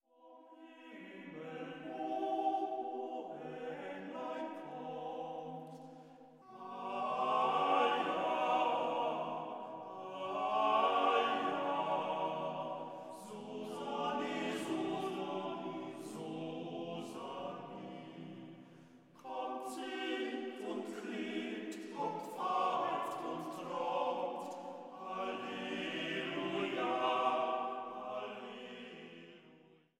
Weihnachtliche Chorsätze und Orgelmusik